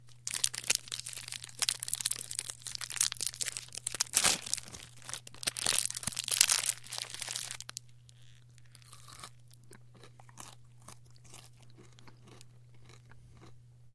Звуки шоколада
Шум шоколадного батончика при открытии и поедании